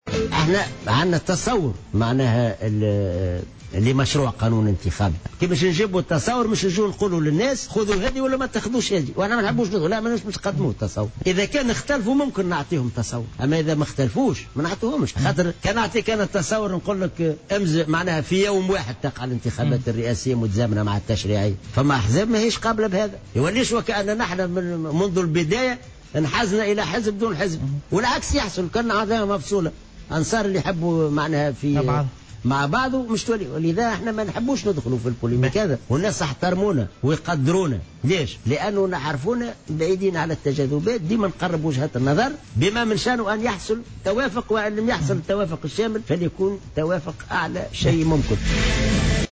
قال الأمين العام للاتحاد العام التونسي للشغل،حسين حسين العباسي في حوار على قناة الوطنية الأولى إن الاتحاد لديه تصوّر للقانون الانتخابي،لكنه لن يعرضه على الأحزاب السياسية إلا في صورة اذا طلب منه ذلك.